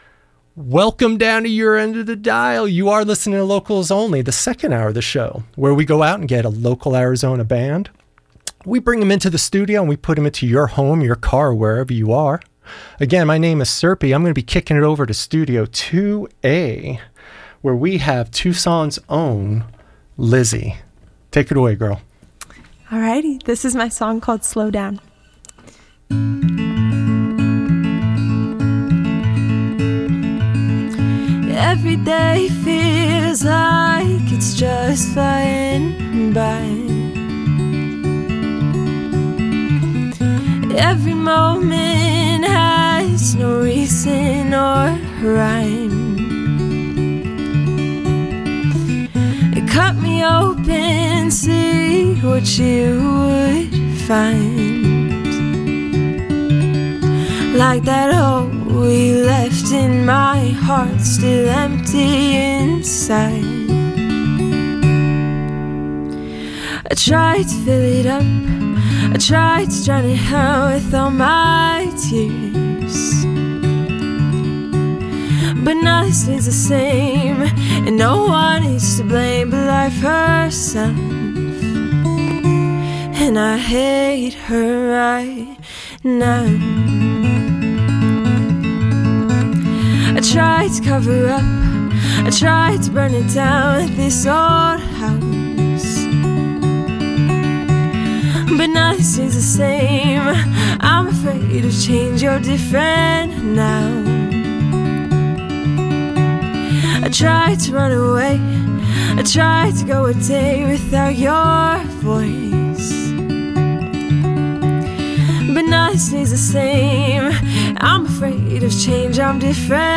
Listen to the live performance + interview here!
live performance
indie-folk
Singer/Songwriter